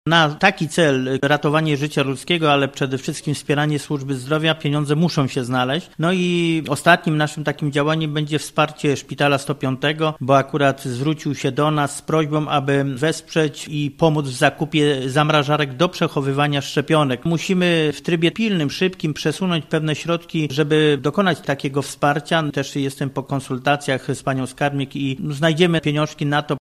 – Zdecydowaliśmy w trakcie sesji budżetowej, że na taki cel musimy znaleźć pieniądze – mówi Józef Radzion, starosta żarski: